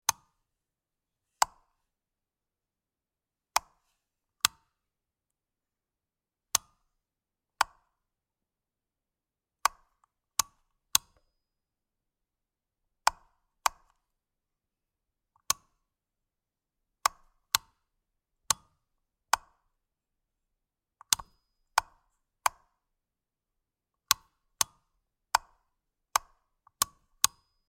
Bakelite light switch